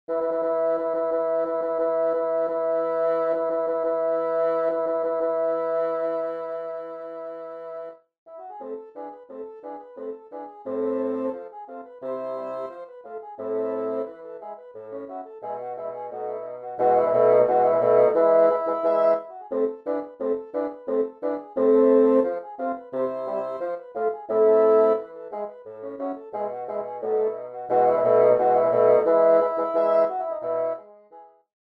Arreglo para quinteto de fagots
Formación: 5 Fagots